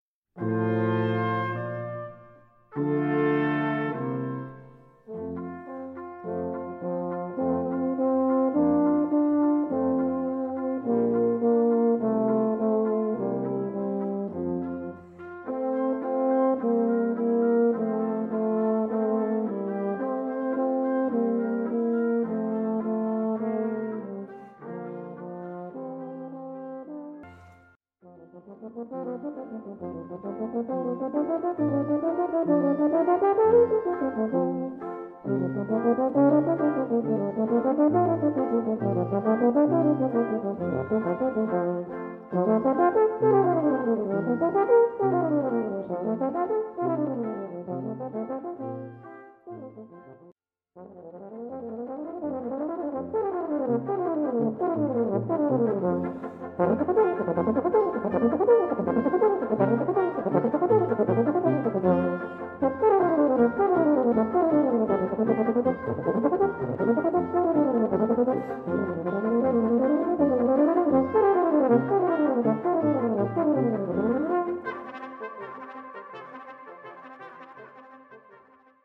For Euphonium Solo
with Brass Quintet.